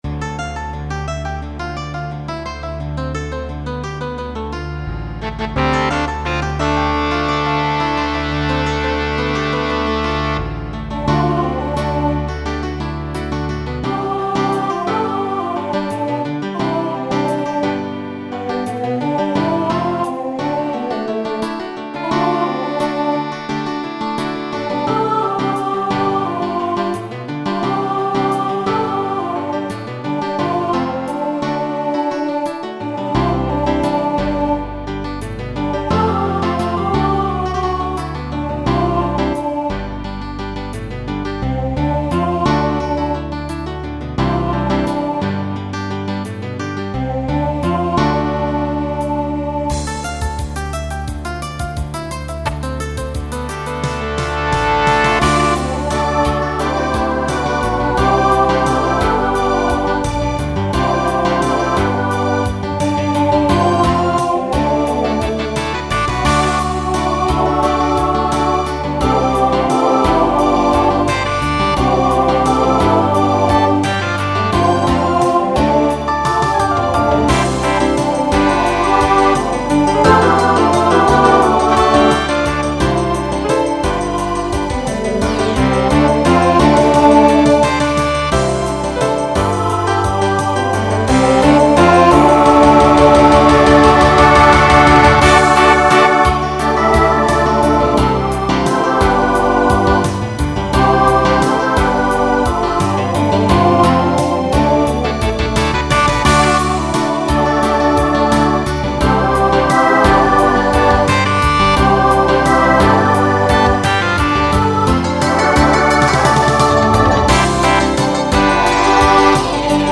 Genre Rock Instrumental combo
Solo Feature Voicing SATB